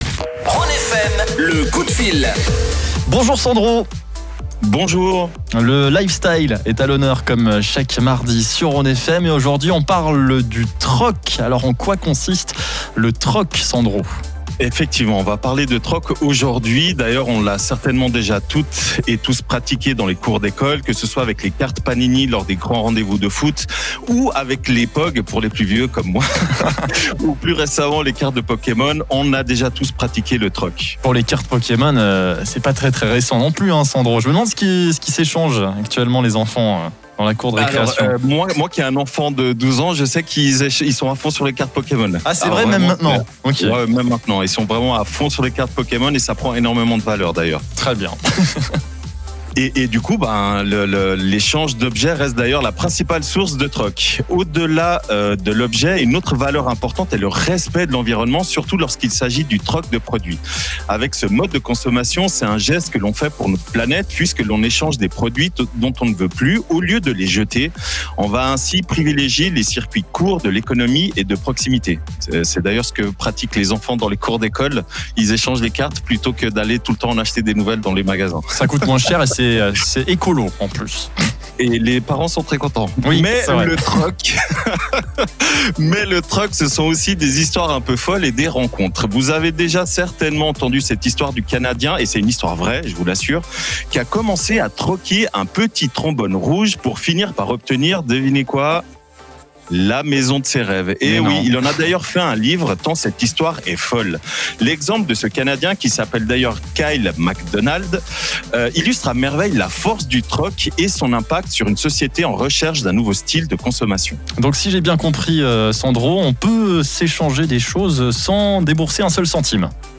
Chaque semaine, nous abordons un thème lifestyle dans la chronique hebdomadaire dans l’émission 16h-19h de RhôneFM.